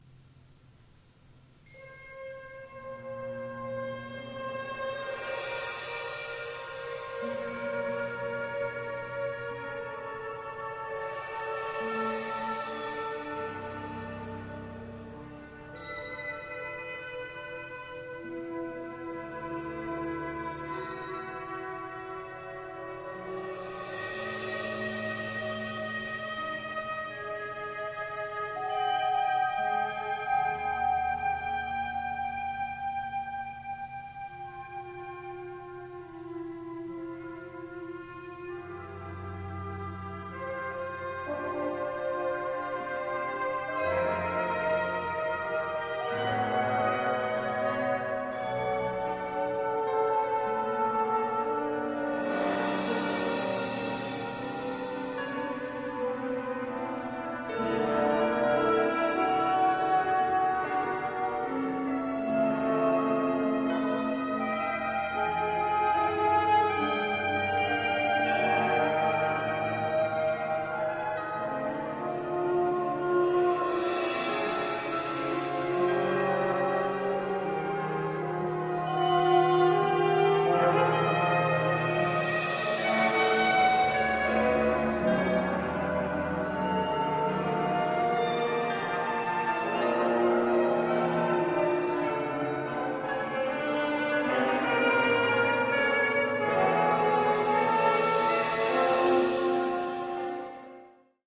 winds and percussion